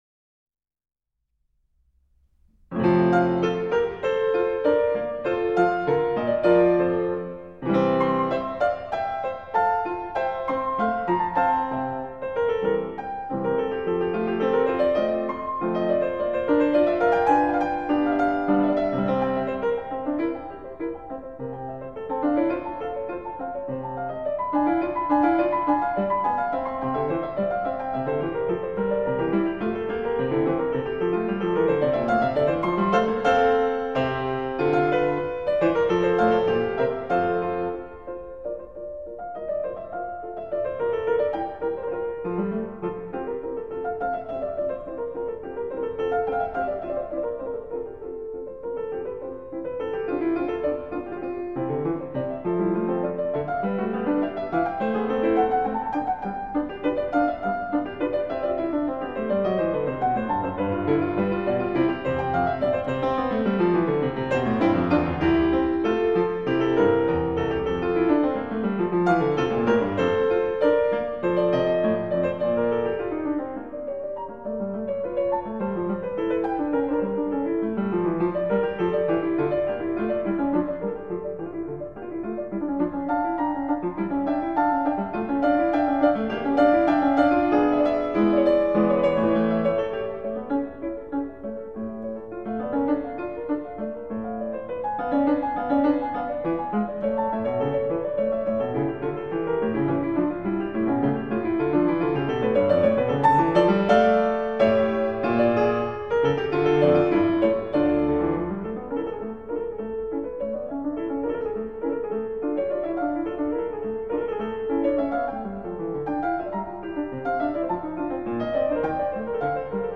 鋼琴演奏家